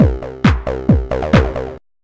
普段あまり使わないパールのワイヤーブラシなる小道具をひととき、ロック・ドラミングのスティックから持ち替えて、60年代風のビートを刻んでみた。フォー・ビートをいい感じでカンカンと叩くまではいっておらず、モノリスに触れた直後の人類よろしく数分間のウォーミングアップの後に音が引き締まってくるまでのちょっとした過程を、生収録によるRealプレーヤーで聴ける